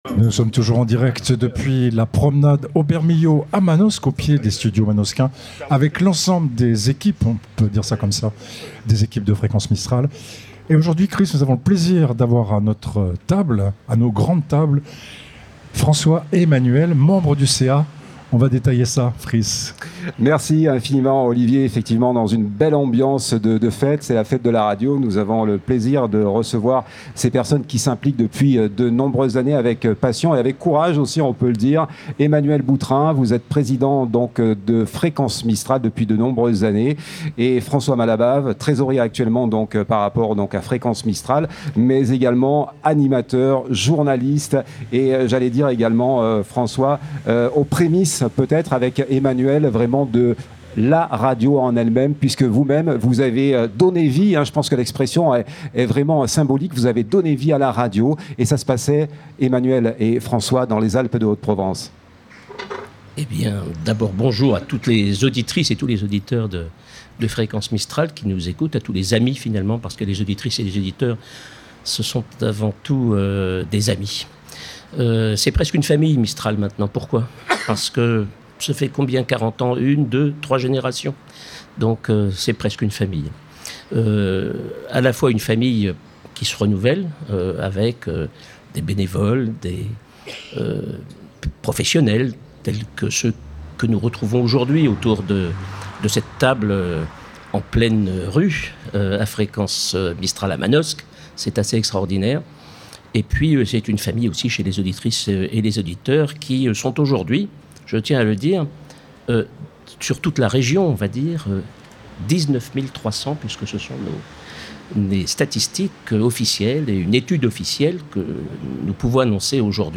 A cette occasion, toute l'équipe de Fréquence Mistral s'est retrouvée afin de vous proposer un plateau délocalisé en direct sur toute la journée sur Manosque. Retrouvez ci-dessous la rediffusion de l'interview du bureau de l'association : Fete de la Radio 2025 - ITW du Bureau.mp3 (57.99 Mo)